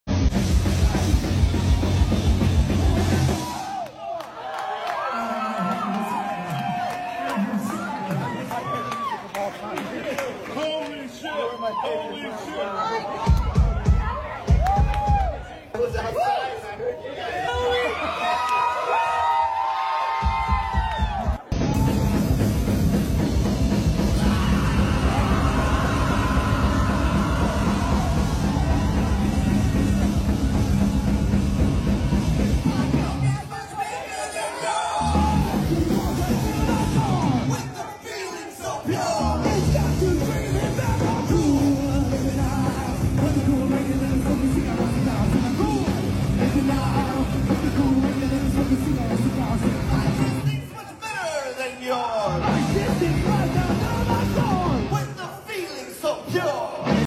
en el Cubby Bear de Chicago